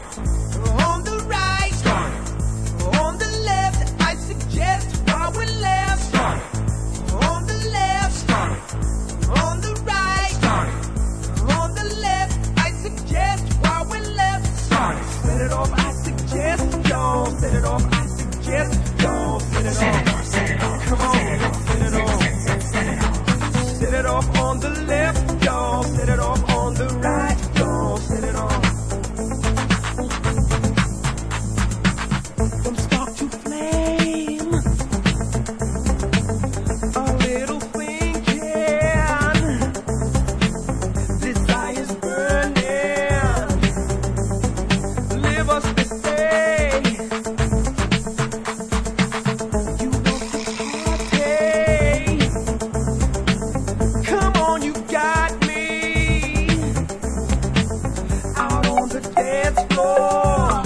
Classic party starter
A hybrid of early eighties disco and electro. Feel the 808!!